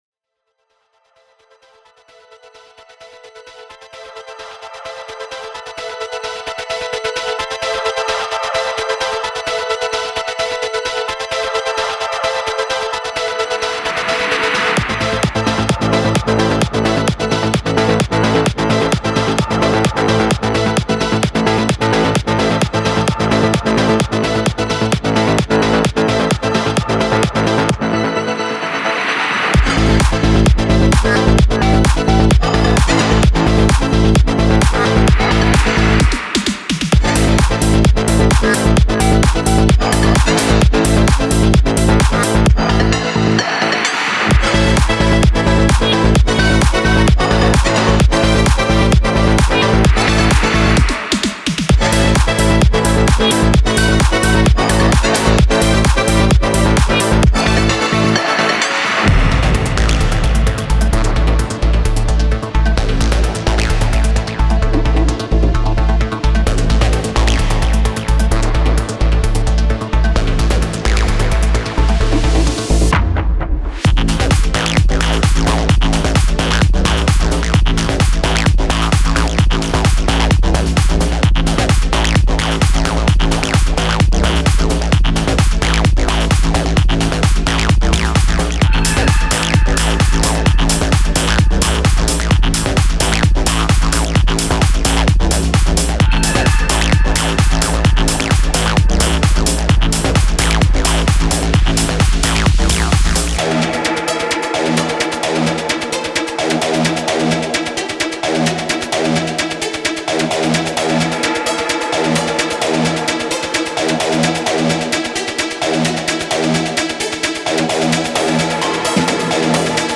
Genre:Techno
デモサウンドはコチラ↓